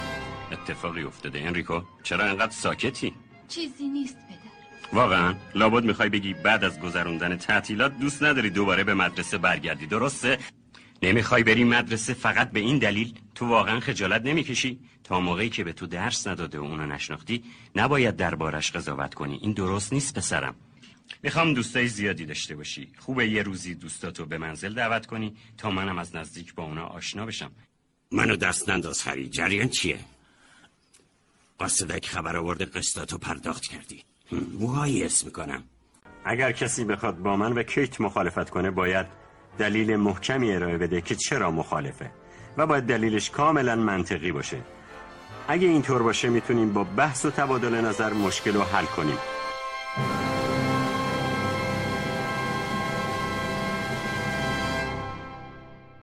وی از گویندگانی است که صدای رسا و گرم او باب طبع نقش های افراد میانسال و جا افتاده بود.